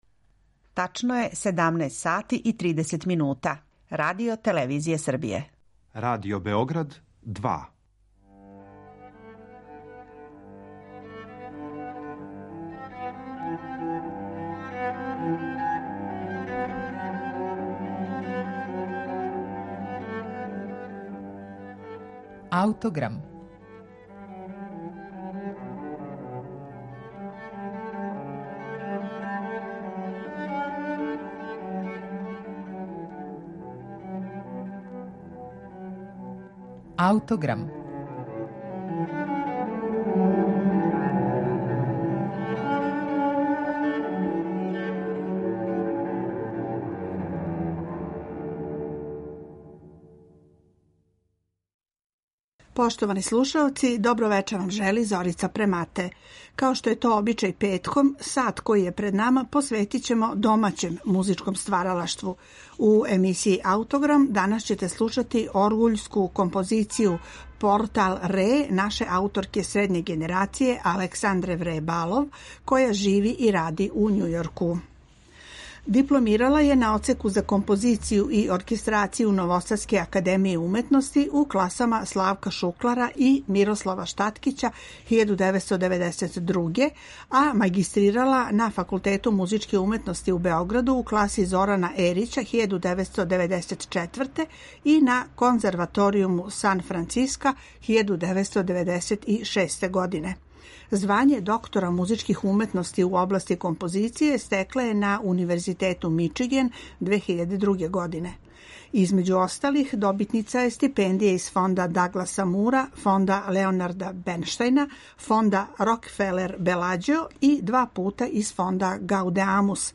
Дело за оргуље „Портал РЕ" ауторка је написала поводом 200-годишњице Краљевске музичке академије у Лондону, а као једно од 200 остварења која су за тај јубилеј, по позиву, написали разни композитори света.